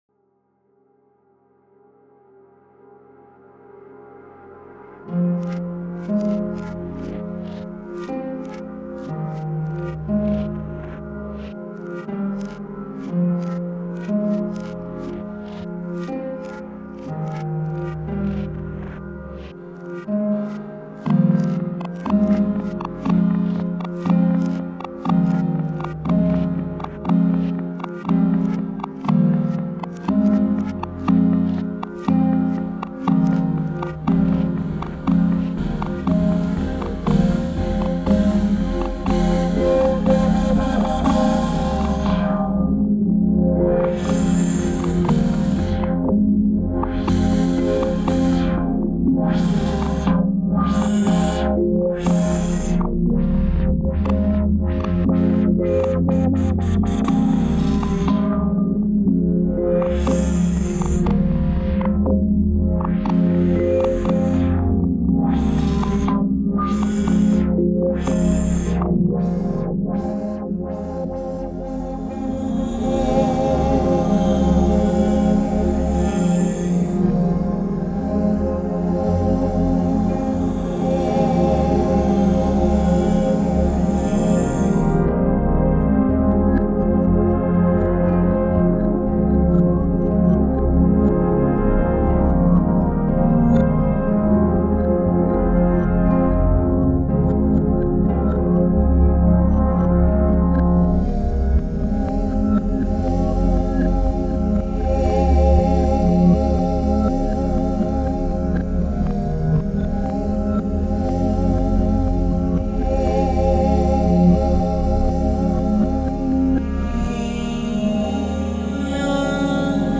過去作の逆再生に編集を加えるという実験的手法で作った楽曲。
タグ: アンビエント 不気味/奇妙 変わり種 コメント: 過去作の逆再生に編集を加えるという実験的手法で作った楽曲。